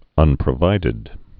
(ŭnprə-vīdĭd)